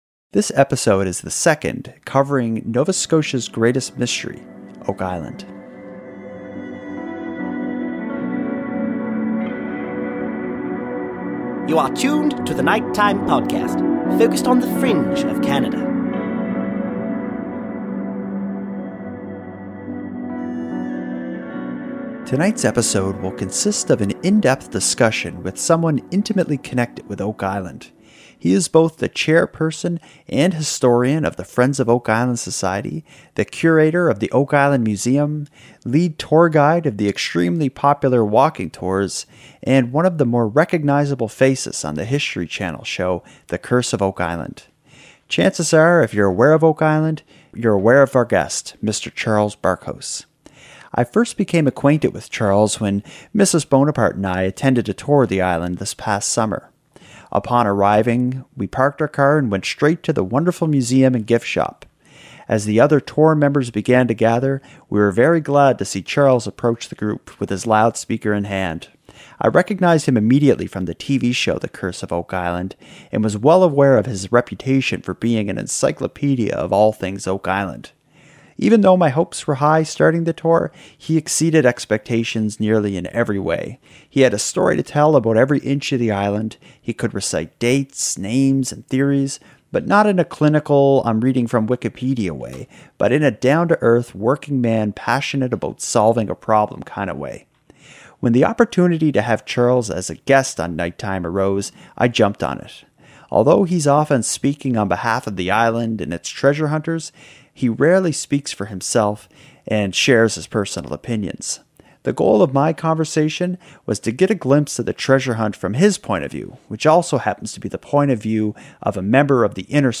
an in depth discussion of all things Oak Island.